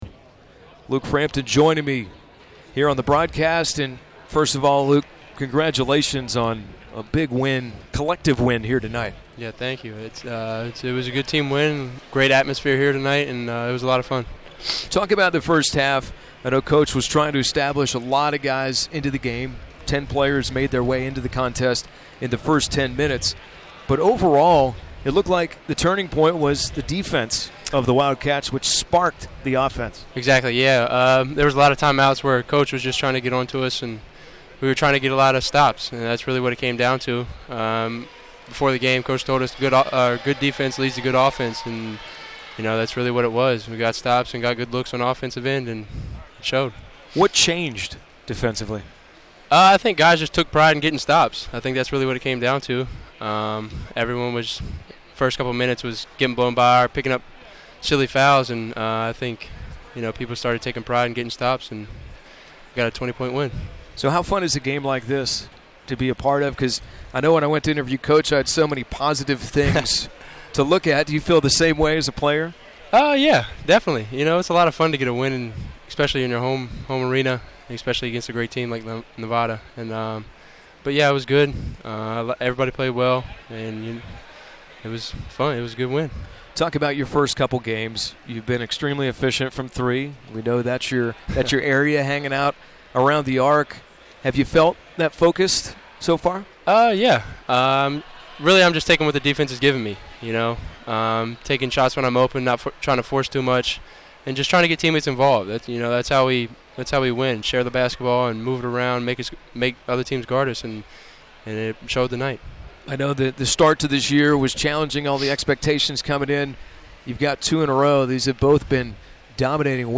Radio Interview